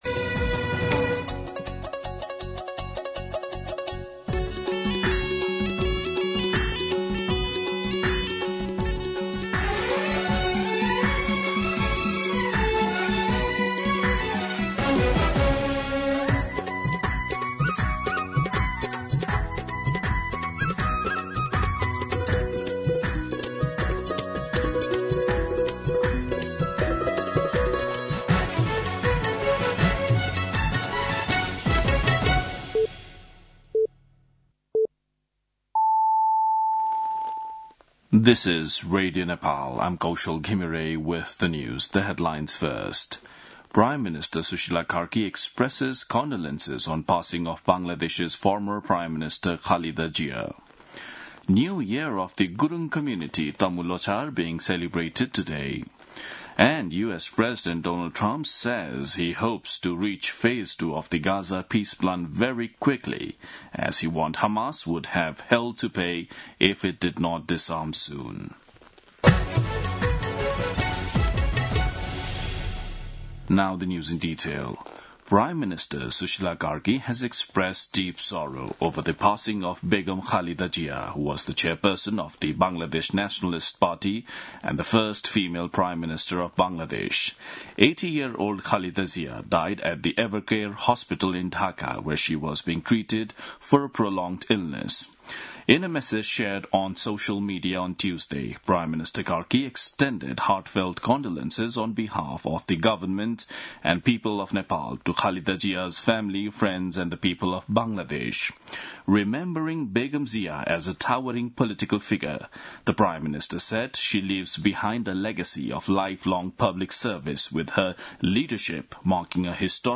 An online outlet of Nepal's national radio broadcaster
दिउँसो २ बजेको अङ्ग्रेजी समाचार : १५ पुष , २०८२